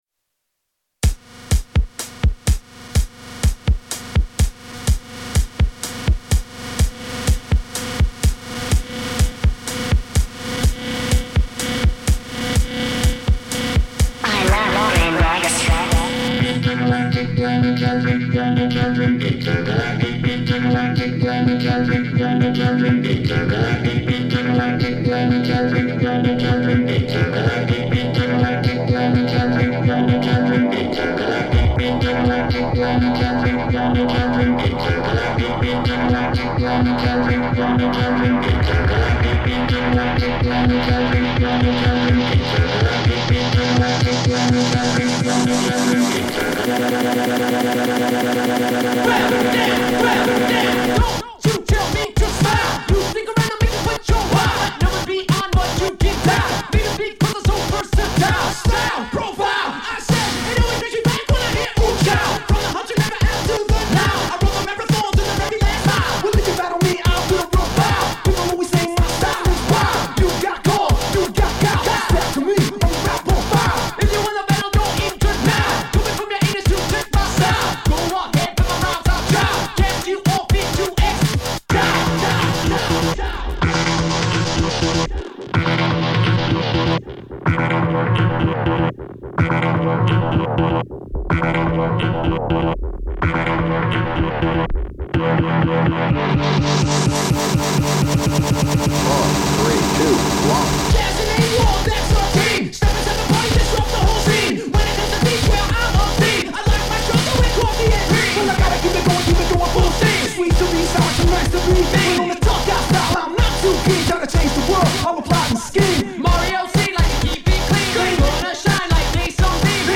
De l’electro qui fait mal.
Some electro shit that hurt.